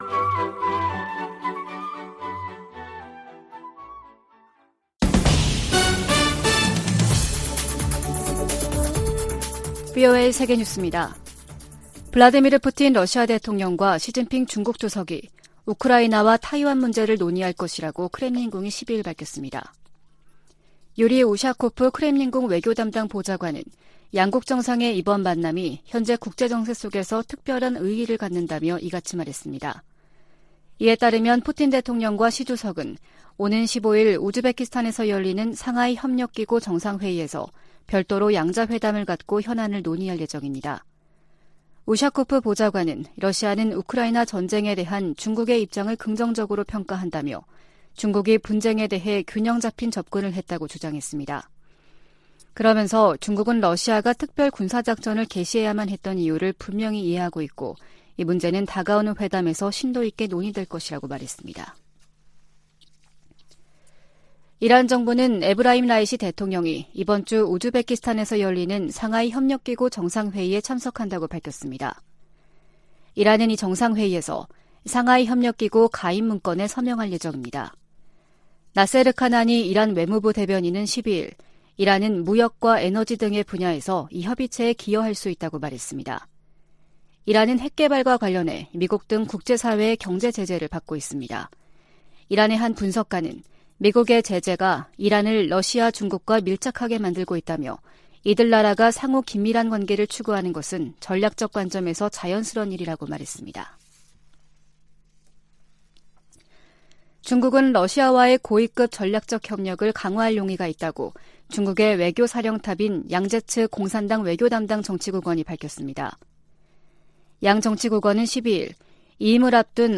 VOA 한국어 아침 뉴스 프로그램 '워싱턴 뉴스 광장' 2022년 9월 14일 방송입니다. 미 국무부는 최근 북한과 중국 항구에서 수상한 움직임이 잇따라 포착되는 데 대해 제재 이행을 거듭 촉구했습니다. 북한 풍계리 핵실험장 3번 갱도에서 핵실험 준비 정황이 계속 관찰되고 있다고 국제원자력기구(IAEA) 사무총장이 밝혔습니다.